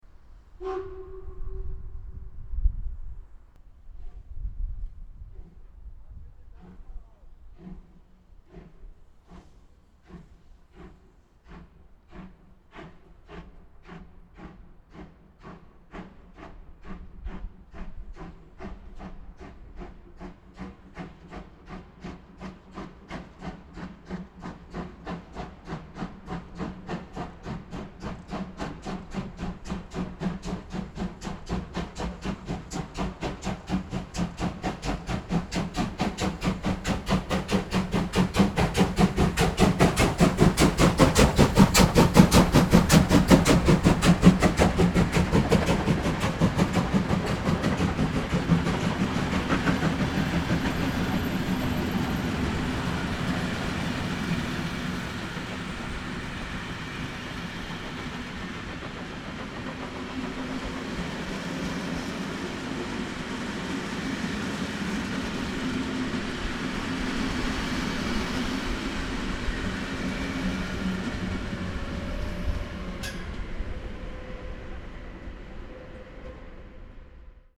Daf�r gibt es heute erstmalig auch eine Sound Datei! 23:12 Uhr - 03 1010 mit DPF 349 (Bad Driburg - Bonn Hbf) Sounddatei 03 1010 bei der Ausfahrt aus Solingen Hbf: 03_1010_dpf349_kso_160313.mp3